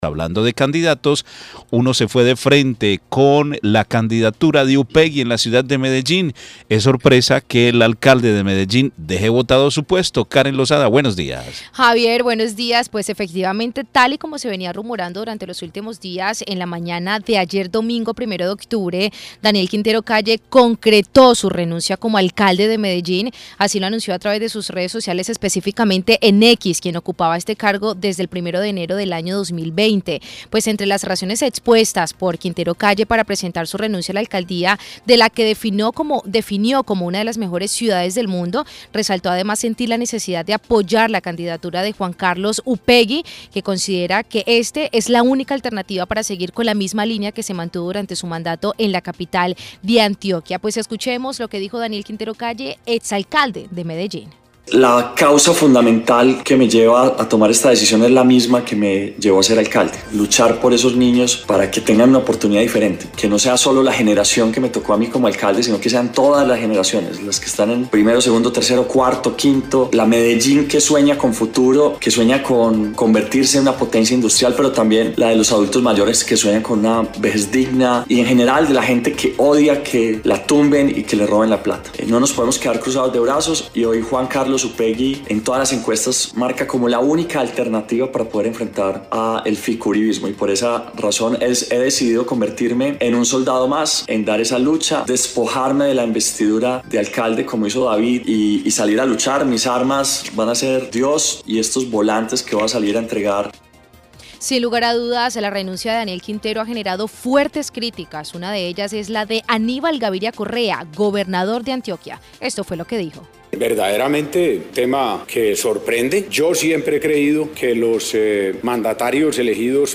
Esto dijo Daniel Quintero Calle, Ex Alcalde de Medellín.